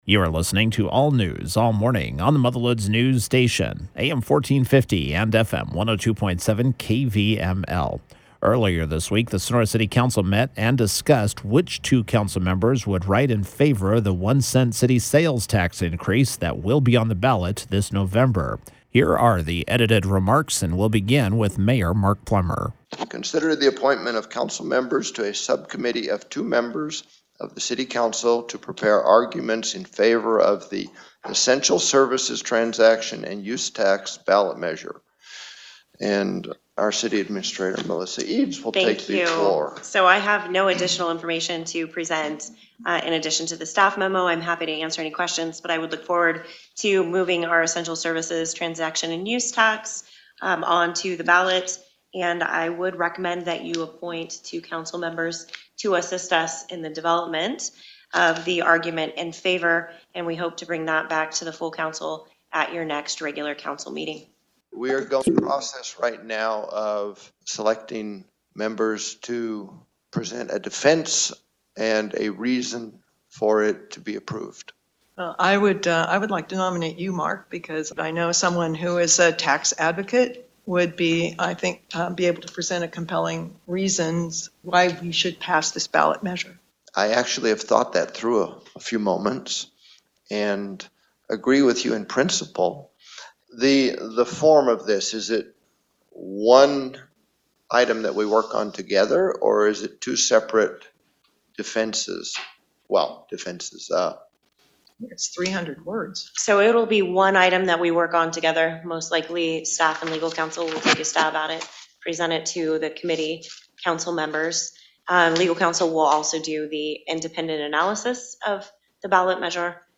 At the meeting earlier this week, the council had the task of picking two members to help author an argument in favor that will be in the official voter guide. Here is the edited audio of that meeting: